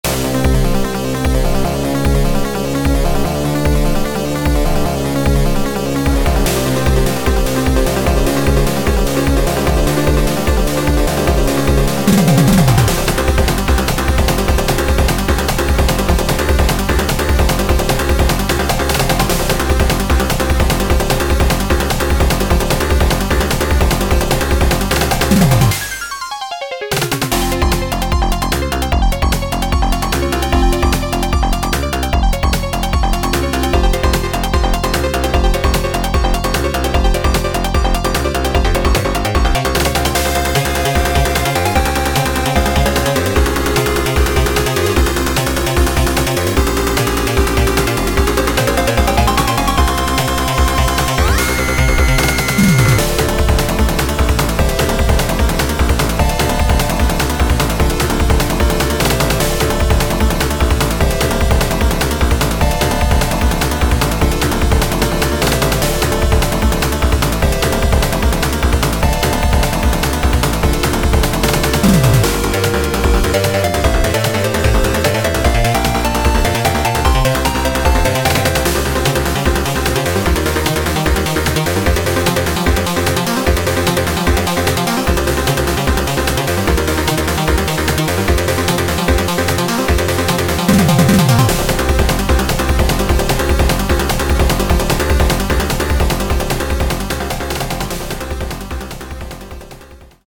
ドラムパートをのぞけばメロディーはほとんどいじってません。